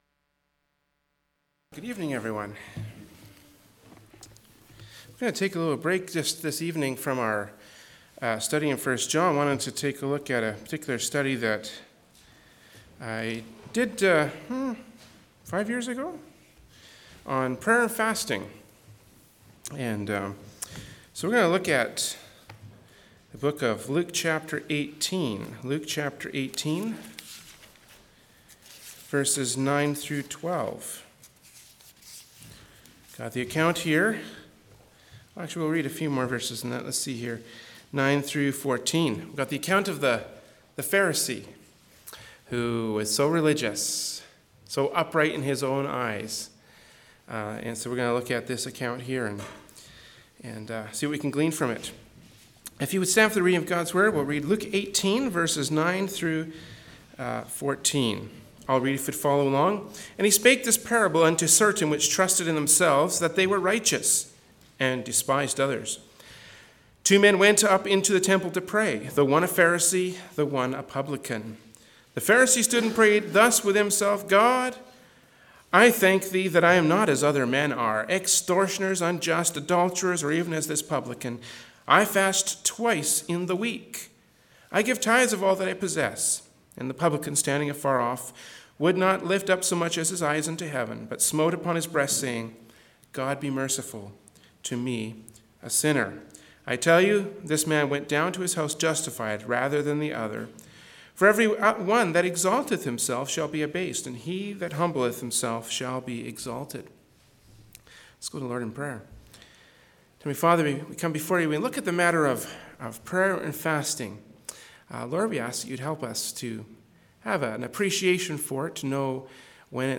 Genre: Preaching.
Luke 18:9-12 Service Type: Wednesday Evening Service “Luke 18:9-12” from Wednesday Evening Service by Berean Baptist Church.